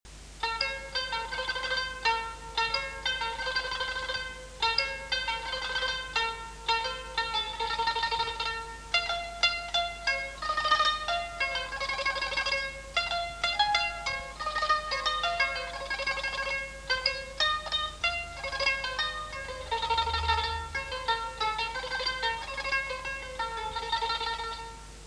Instruments traditionnels arméniens
Kanoun
Le kanoun est une cithare sur table ; il a 72 à 75 cordes de boyau ou de nylon, groupées par trois, accordées selon la gamme diatonique, chaque groupe de 3 cordes accordé à l'unisson, donnant ainsi en tout 24 sons différents.
Instrument soliste et d'accompagnement, le joueur pince les cordes de sa main gauche avec un léger retard sur la main droite, de façon à créer une mélodie hétérophone et syncopée à une octave d'écart.
kanoun.mp3